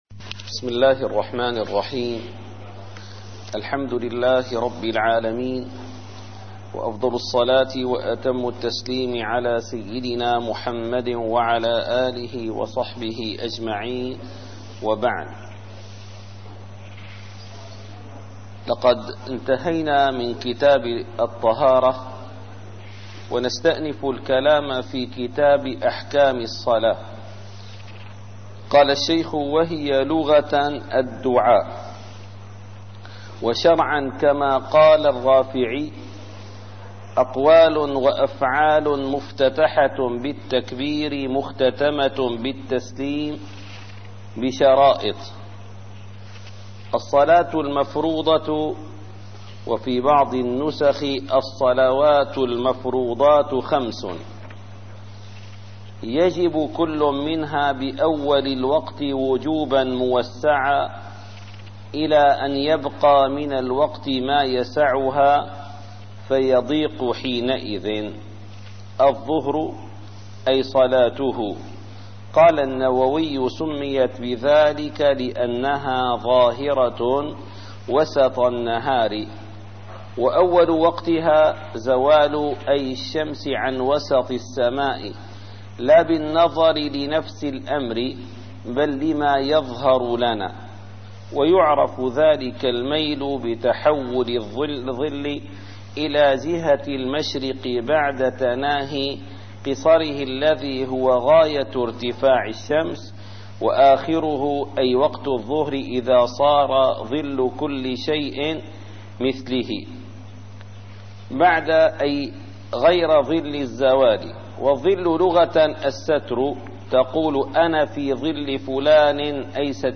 - الدروس العلمية - الفقه الشافعي - شرح ابن قاسم الغزي - الدرس الحادي والثلاثون: كتاب أحكام الصلاة ص56